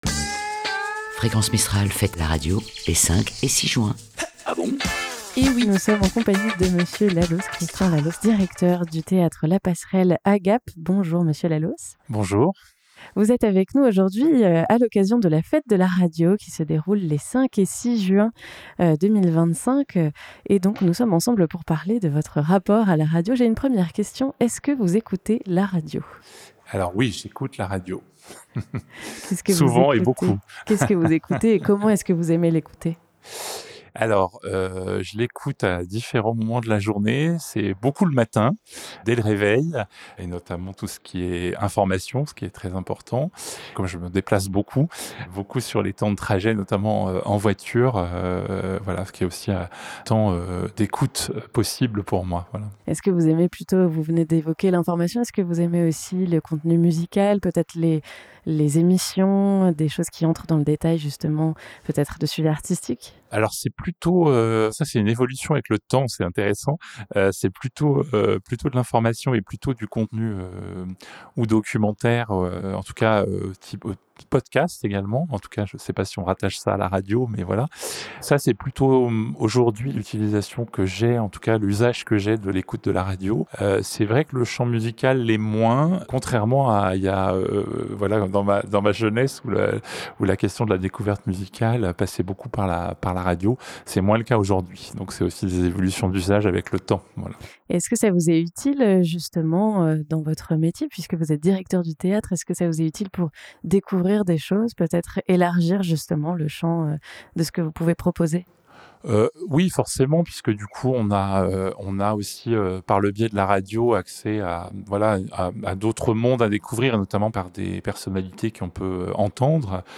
A Gap, c'était l'occasion de proposer des interviews d'acteurs locaux et d'interlocuteurs réguliers, pour mieux apprendre à les connaître. Une manière, aussi, de rencontrer les personnes qui sont en lien, de près ou de loin, avec le média radiophonique et avec Fréquence Mistral.